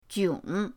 jiong3.mp3